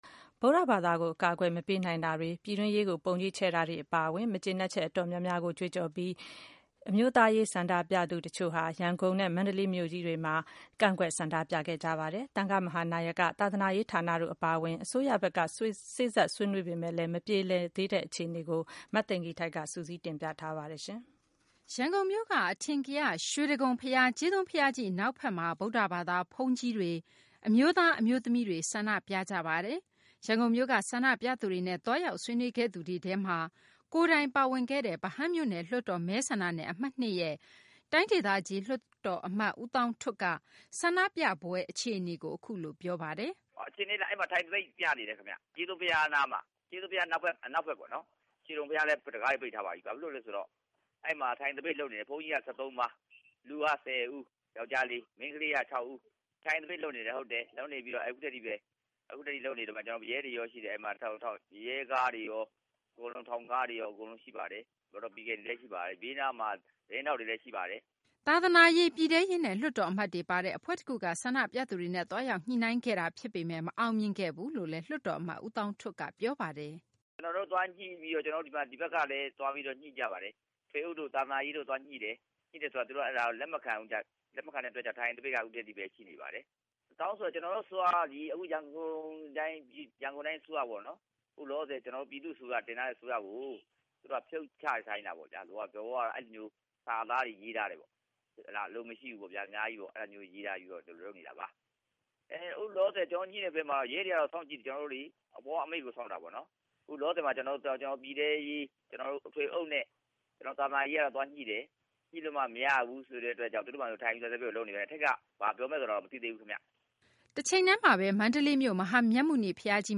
ရန်ကုန်မြို့က အထင်ကရ ရွှေတိဂုံ ဘုရား ကြေးသွန်းဘုရားကြီး အနောက်ဘက်မှာ ဗုဒ္ဓဘာသာ ဘုန်းကြီးတွေ၊ အမျိုးသား အမျိုးသမီးတွေ ဆန္ဒပြကြတာပါ။ ရန်ကုန်မြို့က ဆန္ဒပြသူတွေနဲ့ သွားရောက် ဆွေးနွေးခဲ့သူတွေထဲမှာ ကိုယ်တိုင်ပါဝင်ခဲ့တဲ့ ဗဟန်းမြို့နယ် လွှတ်တော်မဲဆန္ဒနယ် အမှတ် ၂ ရဲ့ တိုင်းဒေသကြီး လွှတ်တော် အမတ် ဦးသောင်းထွဋ်က ဆန္ဒပြပွဲ အခြေအနေကို အခုလို ပြောပါတယ်။